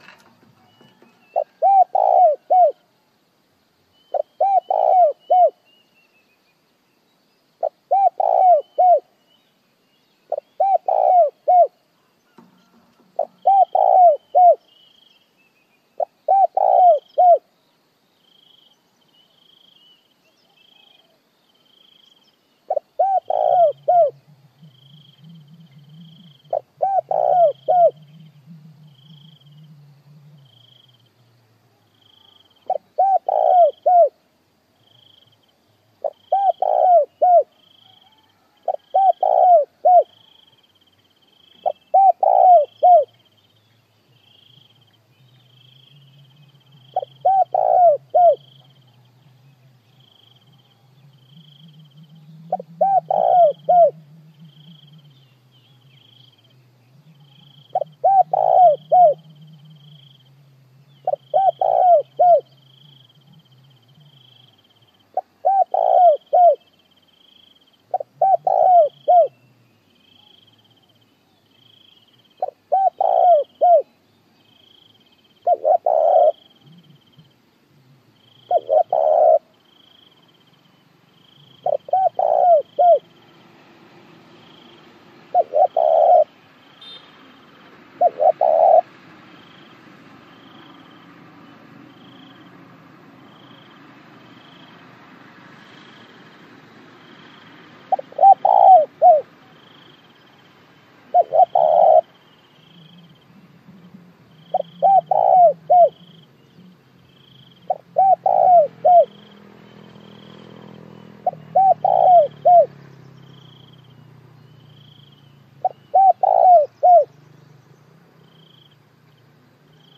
เสียงนกเขาใหญ่ต่อ mp3, เสียงนกเขาใหญ่ต่อเข้าเร็วมาก
เสียงนกเขาชวาร้องหาคู่ เสียงนกเขาร้องหาคู่
หมวดหมู่: เสียงนก